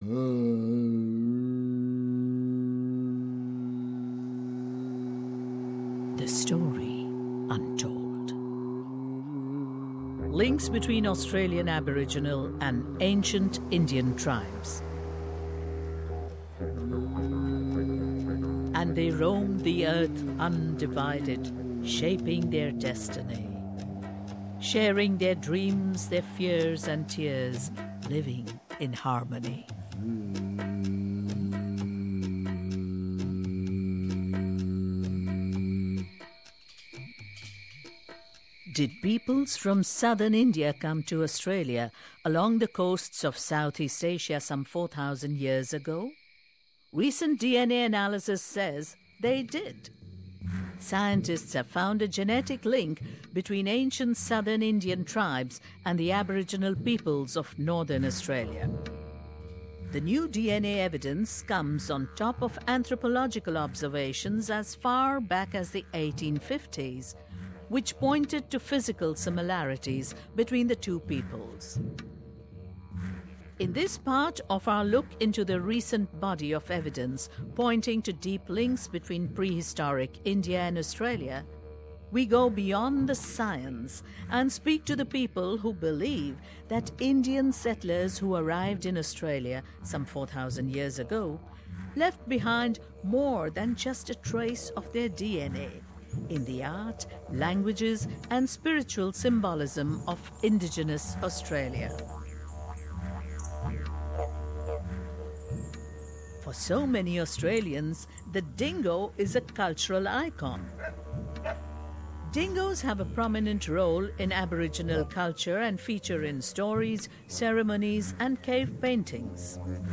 radio special